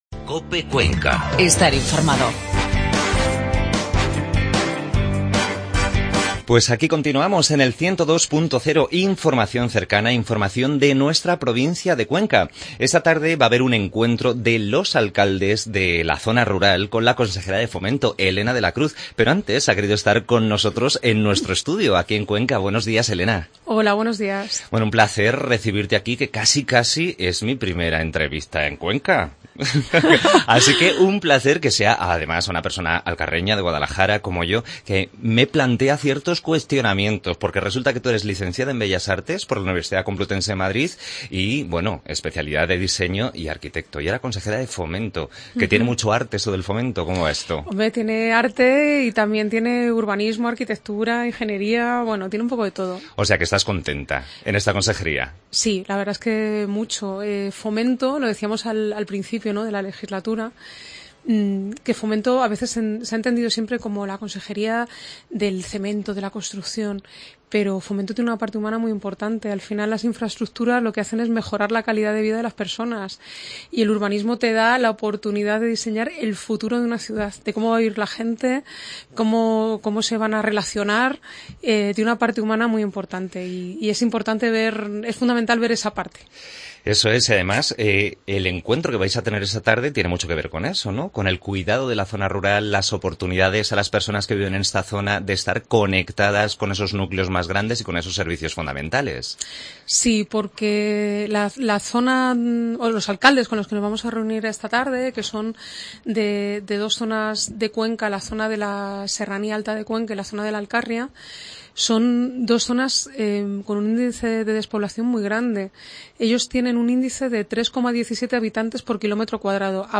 AUDIO: Entrevista a la Consejera de Fomento Elena de la Cruz que presenta esta tarde el nuevo mapa para el transporte rural.